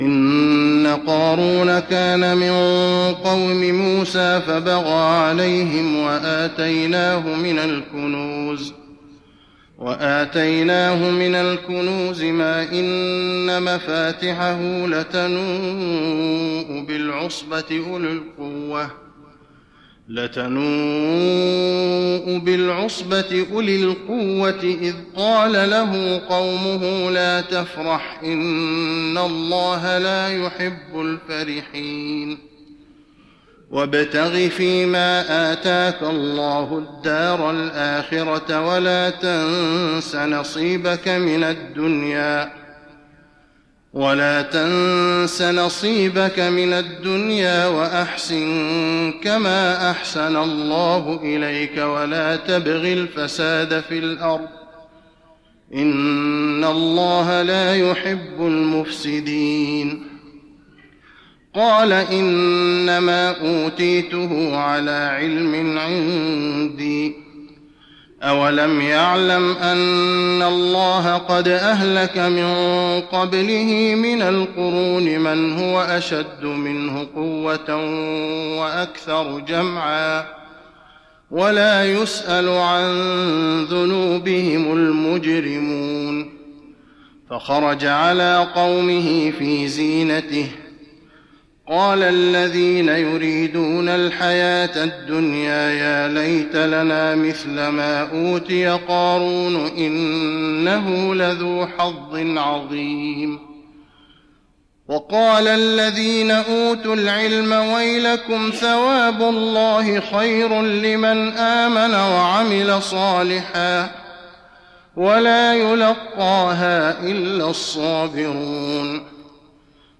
تراويح رمضان 1415هـ من سورتي القصص (76-88) والعنكبوت كاملة Taraweeh Ramadan 1415H from Surah Al-Qasas and Al-Ankaboot > تراويح الحرم النبوي عام 1415 🕌 > التراويح - تلاوات الحرمين